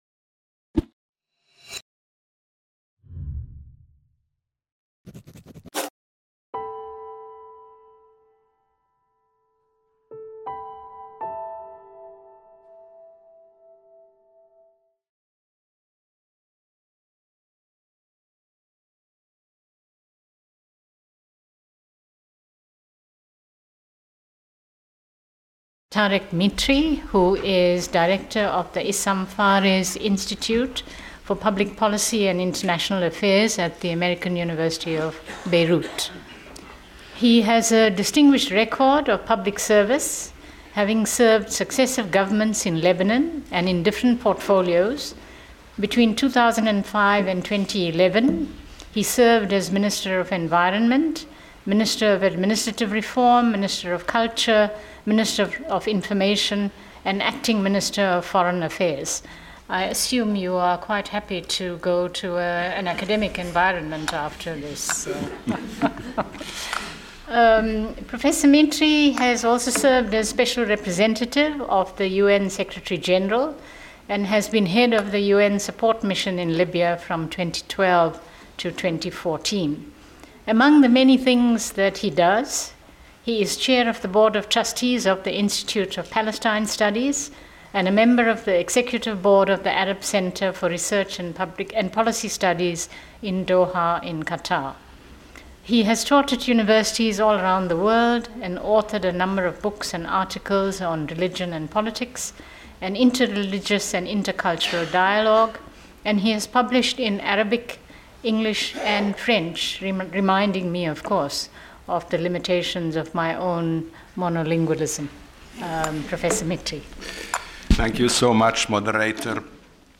Auditorium de l’INALCO 65, rue des Grands Moulins | 75013 Paris